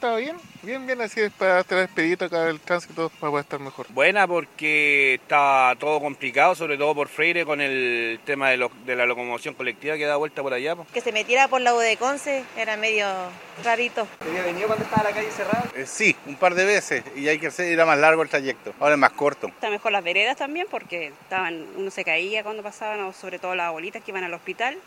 Así lo afirmaron transeúntes que circulan frecuentemente por la zona, quienes además valoraron la mejoría de las veredas.